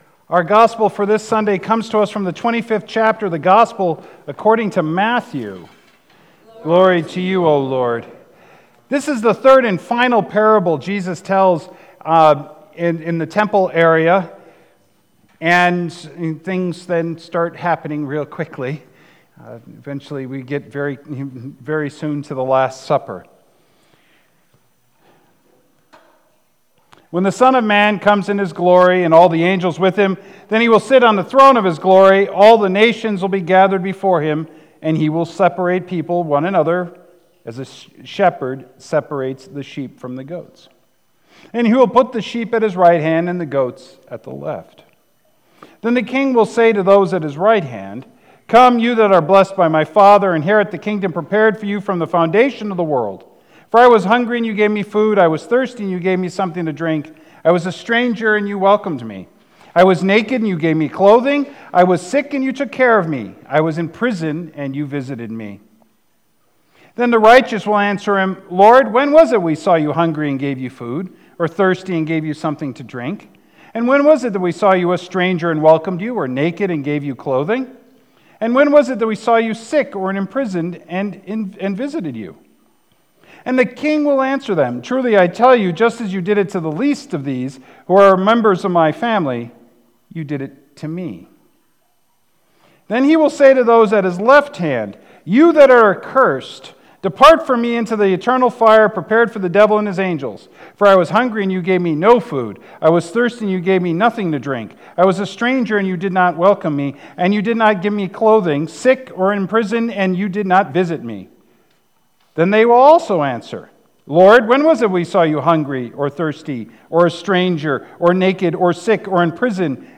Sermons | Beautiful Savior Lutheran Church